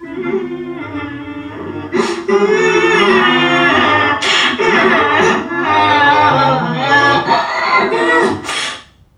NPC_Creatures_Vocalisations_Robothead [57].wav